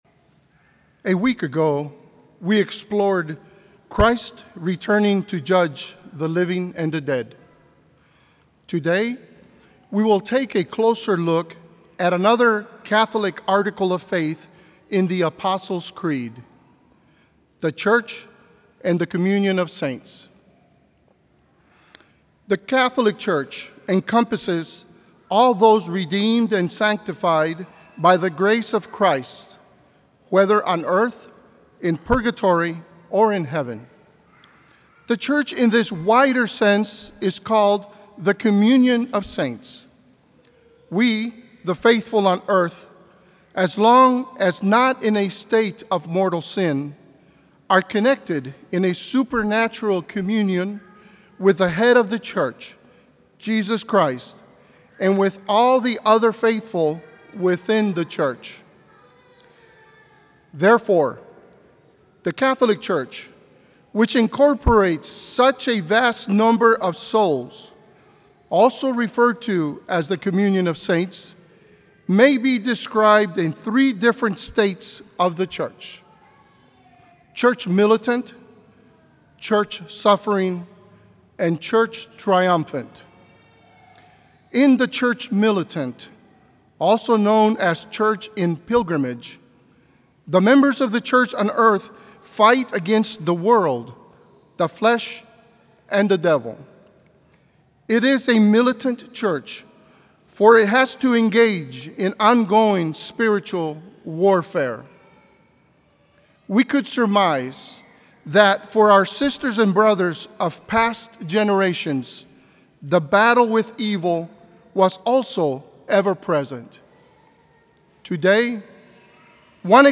Homily
From Series: "Homilies"